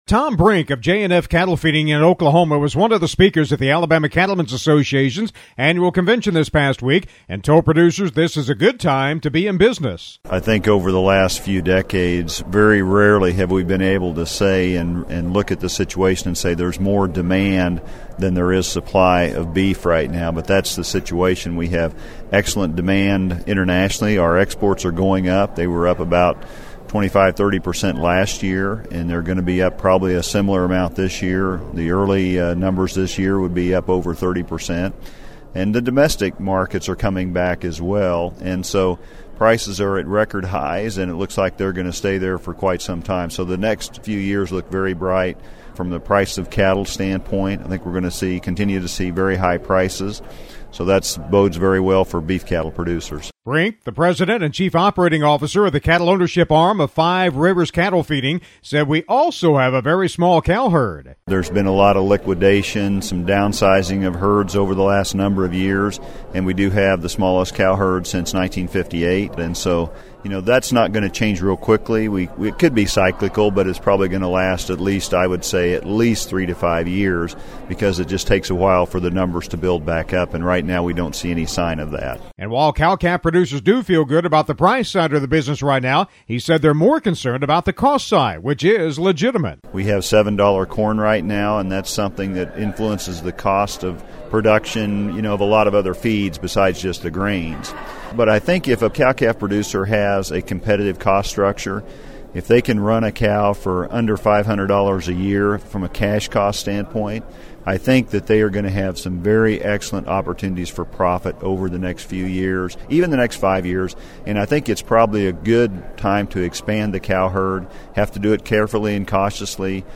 one of the speakers at the Alabama Cattlemen’s Association’s annual convention this past week